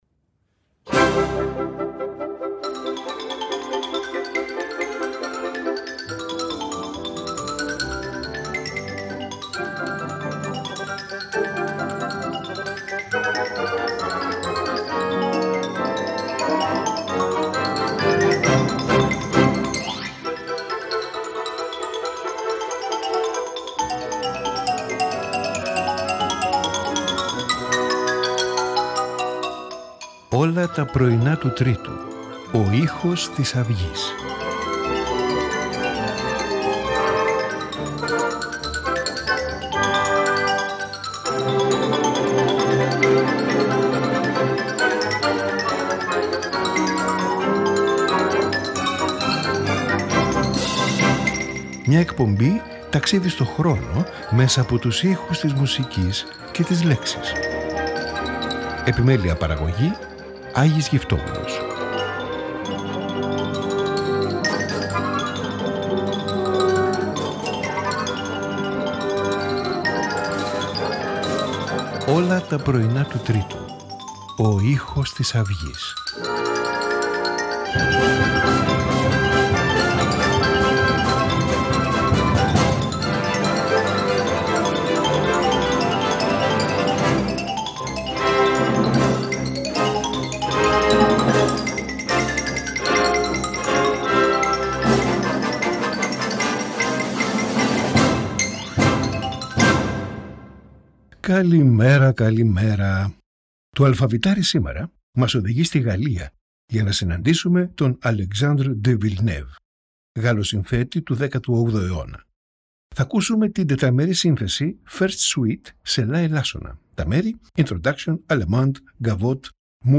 Piano Concerto